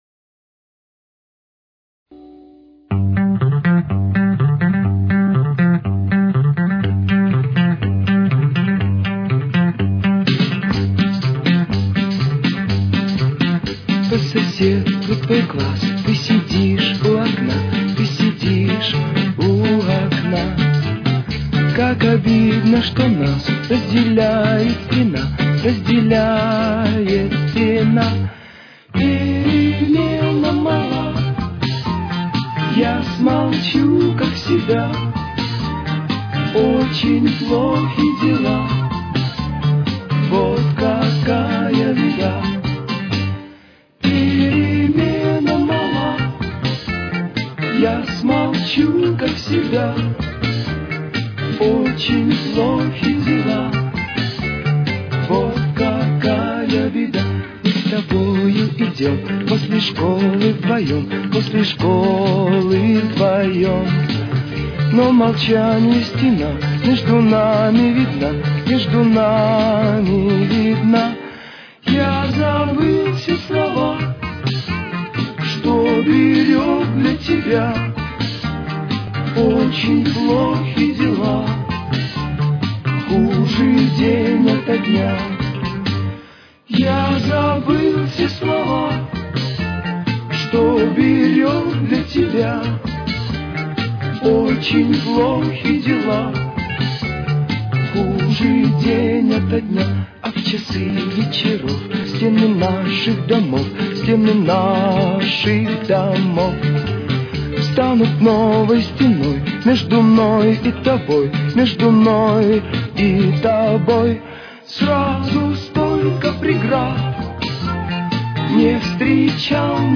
Соль минор. Темп: 131.